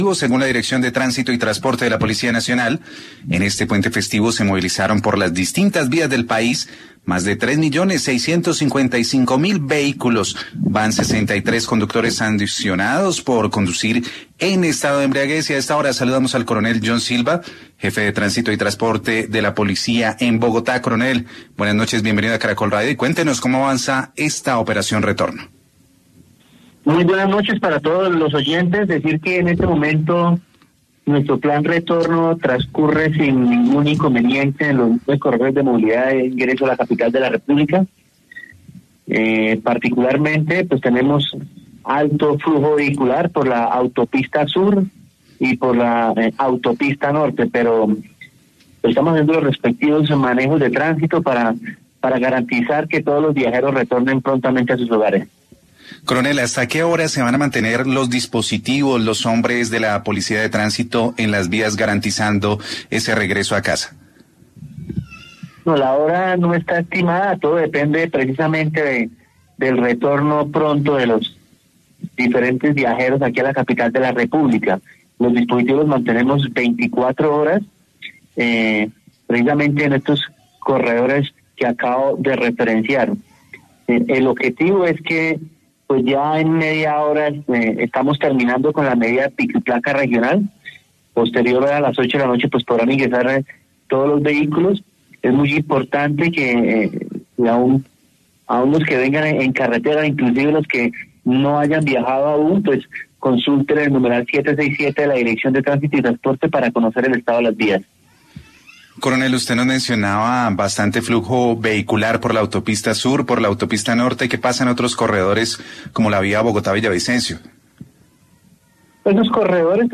John Silva, jefe de Tránsito y Transporte de Bogotá